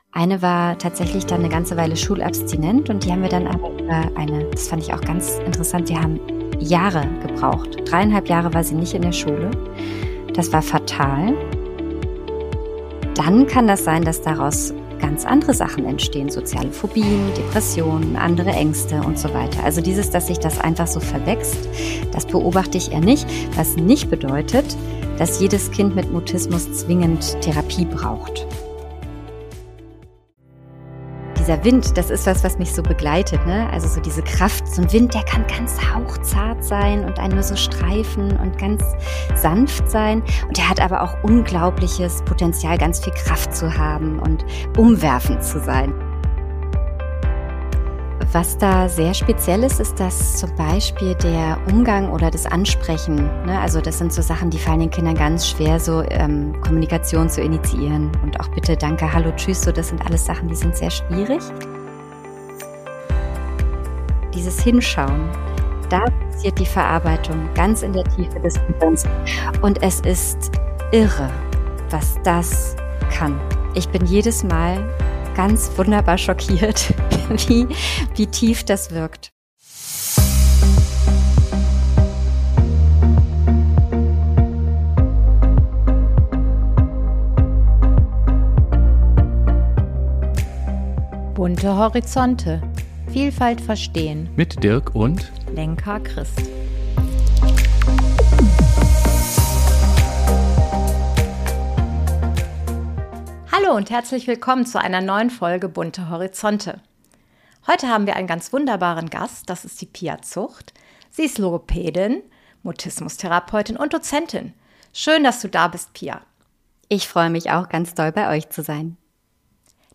Ein Gespräch voller Wissen, Erfahrung und Haltung – für alle, die Kinder mit selektivem Mutismus besser verstehen und begleiten wollen.